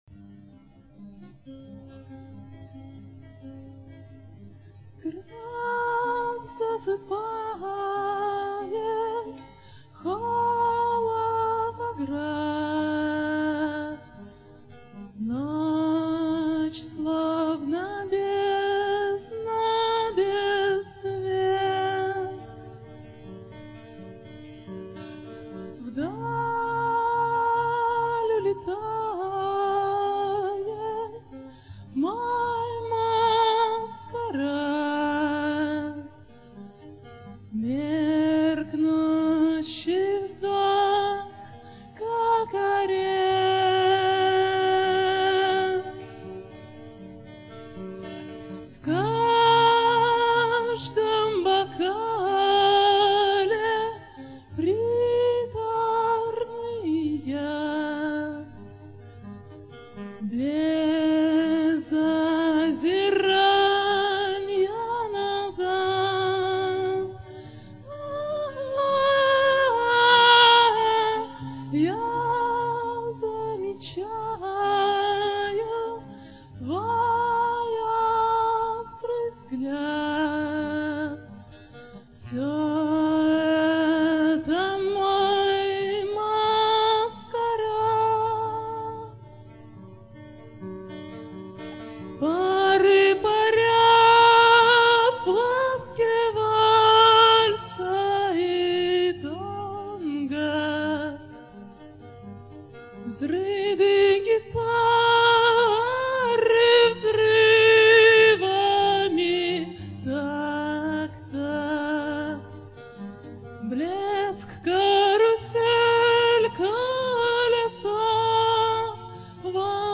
Выступление в театре "Перекресток" 23 января 2001 года.